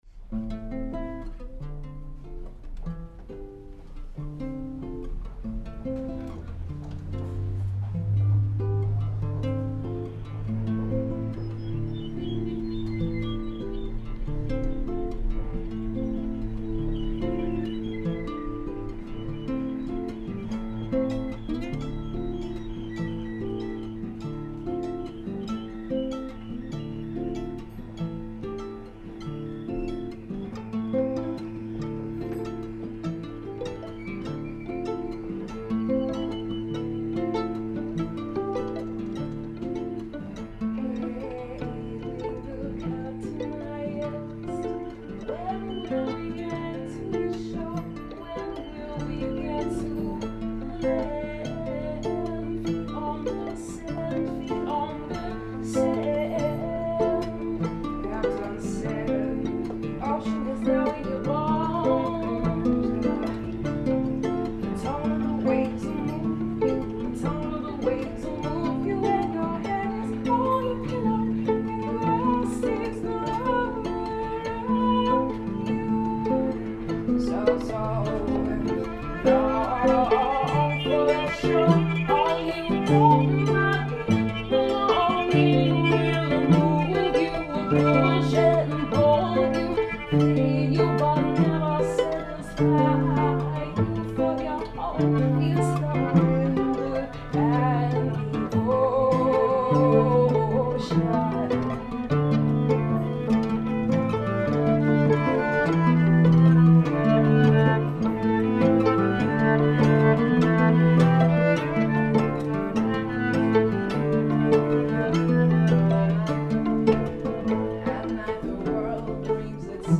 A live collaboration on January 8